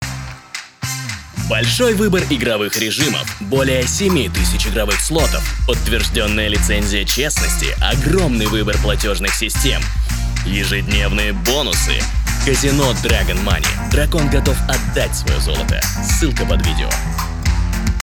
Муж, Рекламный ролик/Средний